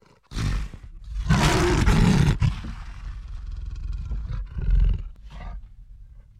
lion-sound